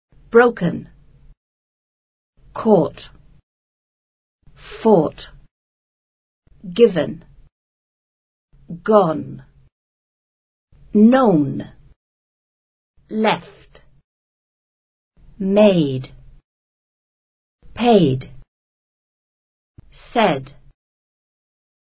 Este archivo de sonido contiene la correcta pronunciación del pasado participio de algunos verbos en inglés.